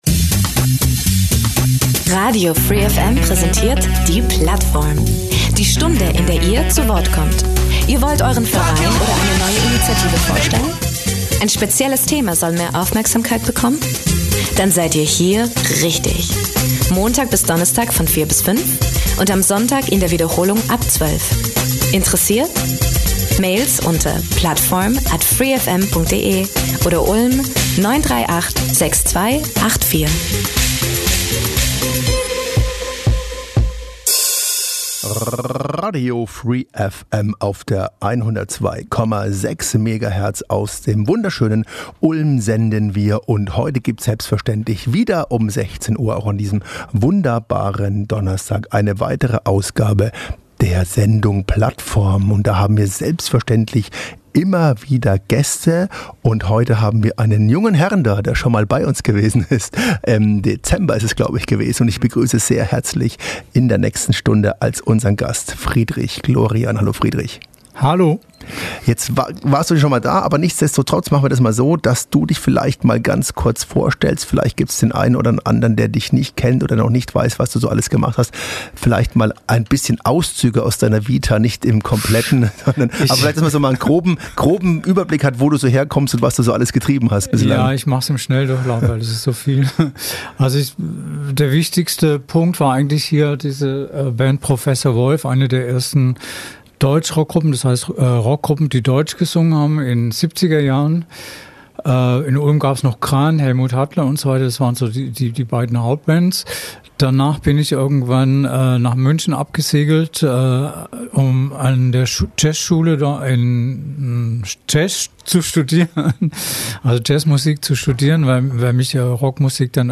Genre Radio